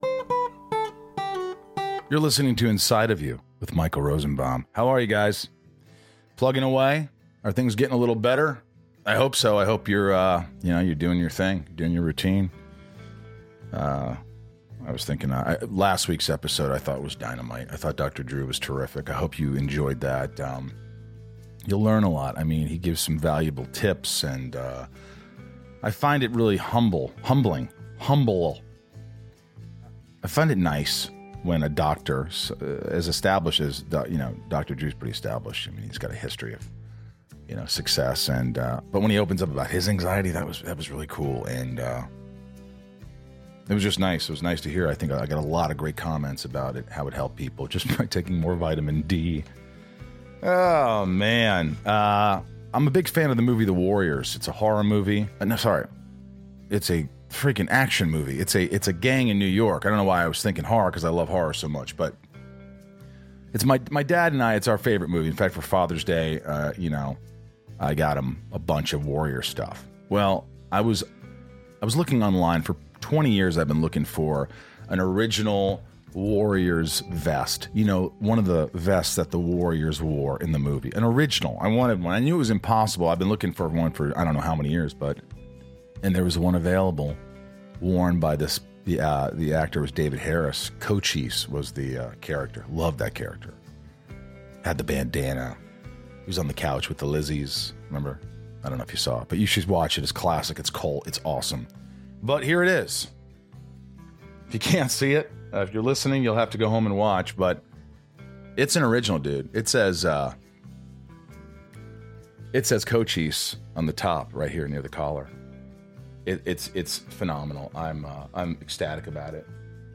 interview-inside-of-you-jennifer-love-hewitt-michael-rosenbaum_tc.mp3